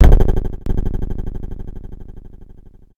Folder: rifle
explode.ogg